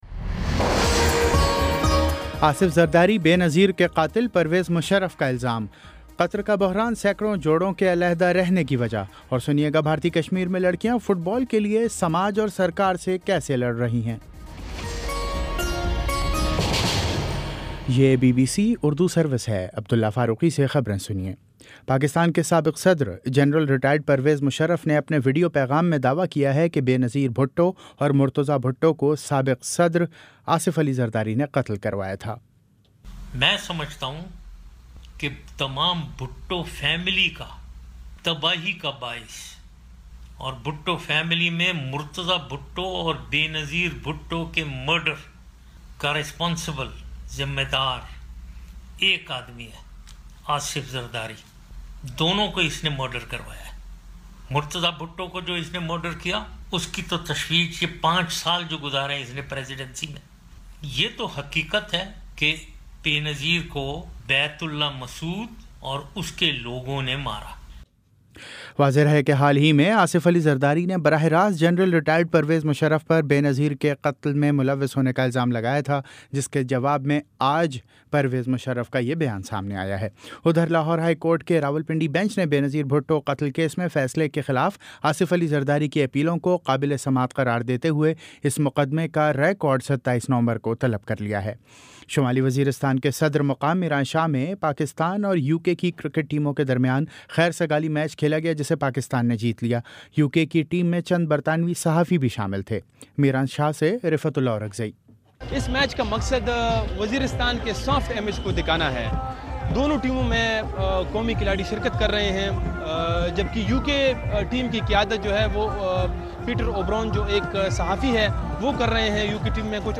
ستمبر 21 : شام چھ بجے کا نیوز بُلیٹن